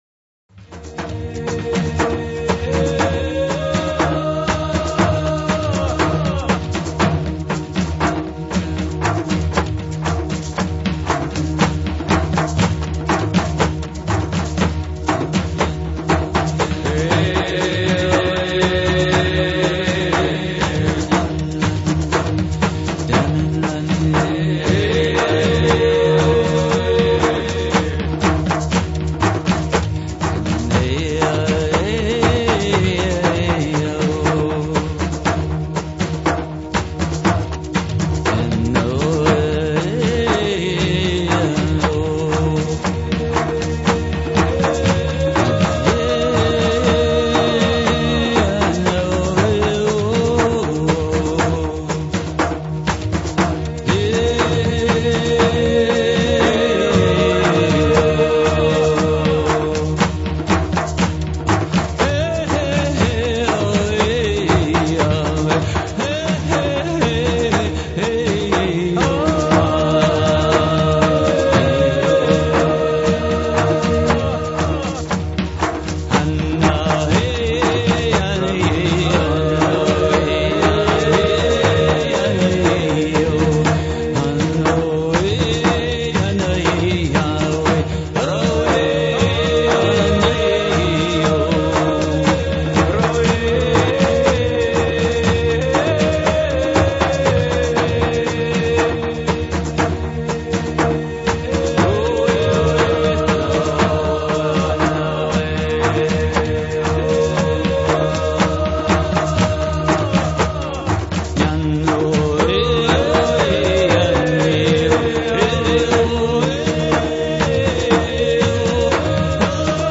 Talk Show Episode, Audio Podcast, DreamPath and Courtesy of BBS Radio on , show guests , about , categorized as
From the sublime to the ridiculous and everything in between. Comedy, music, and a continuous weaving of interviews and story telling (DreamPath style) covering numerous alternative thought topics.